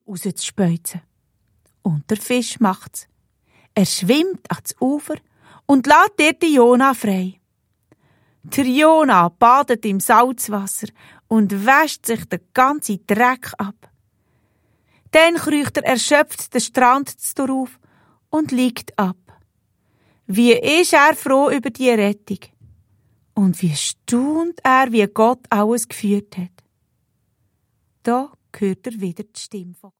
Hörspiel-Album
mit vielen Sprechern gespielt